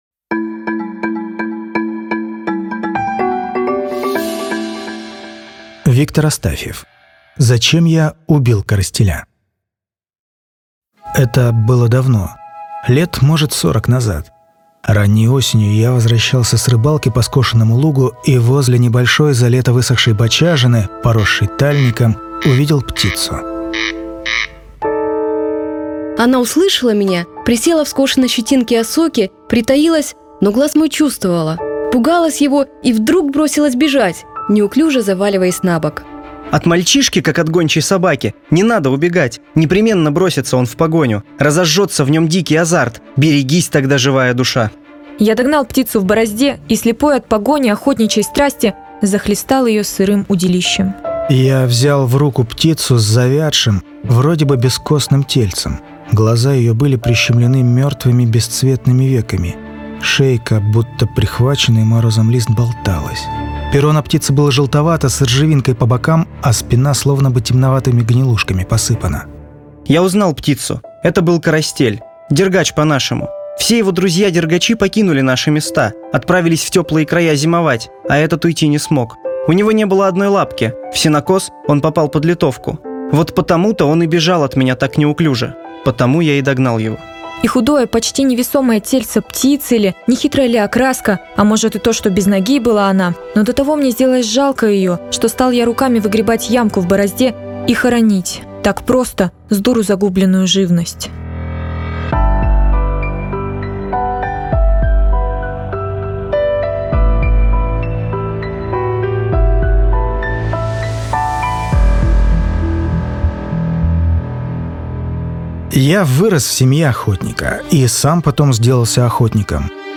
Аудиорассказ «Зачем я убил коростеля?»